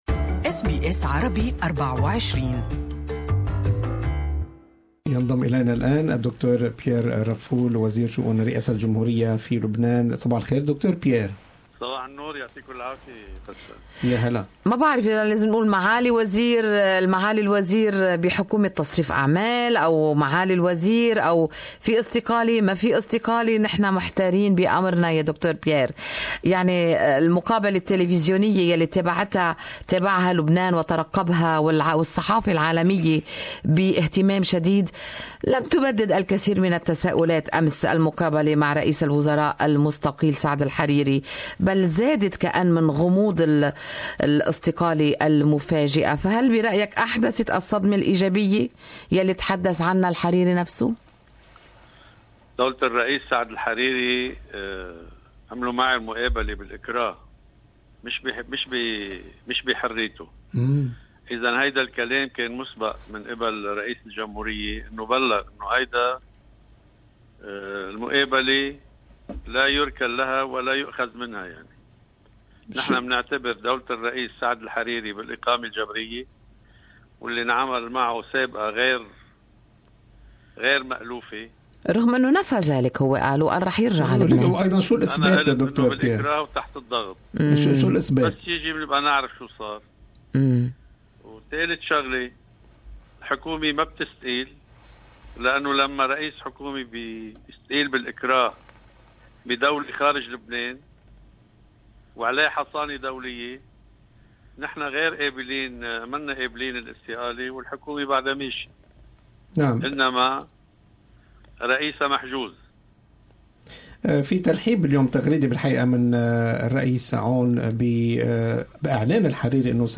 Interview with Lebanese Minister of Presidency affairs, Pierre Raffoul within Good Morning Australia. His excellency pointed out that Lebanese PM Saad Hariri is currently placed under house arrest in Riyadh.